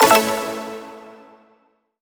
button-solo-select.wav